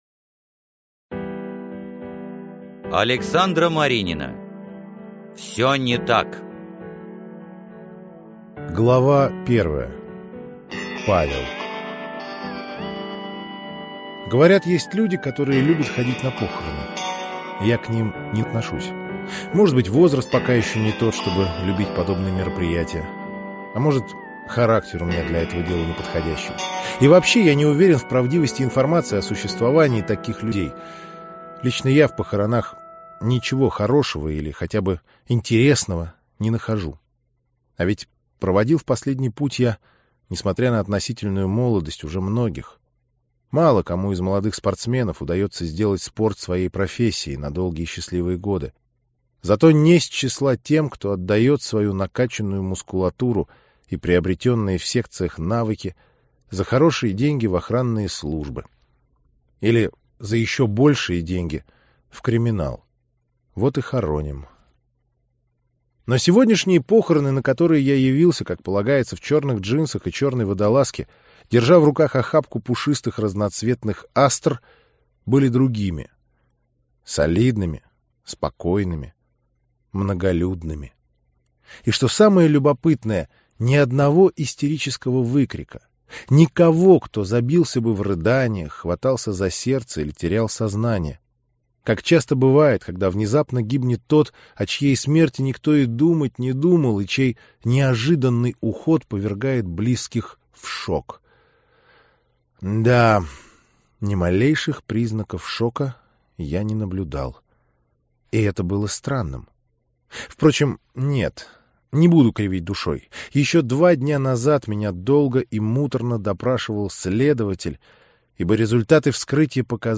Аудиокнига Всё не так - купить, скачать и слушать онлайн | КнигоПоиск